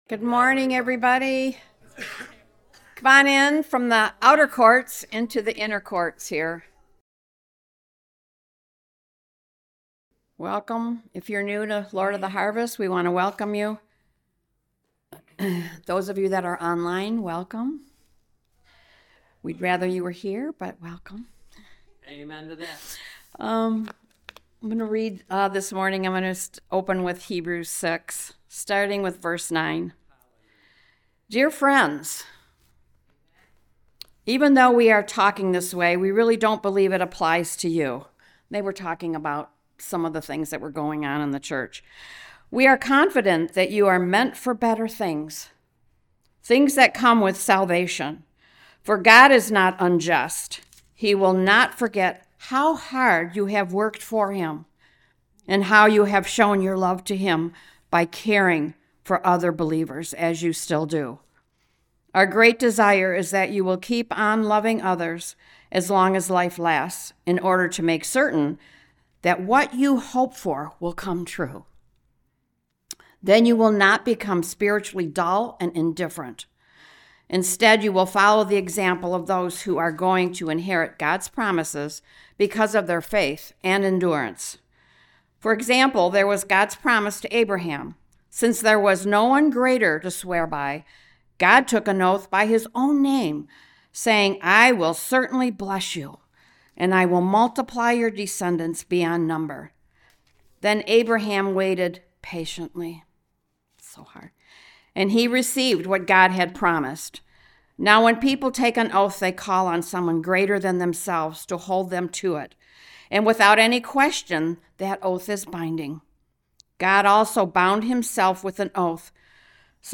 Series: Prophetic Nature of the Church Service Type: Sunday Service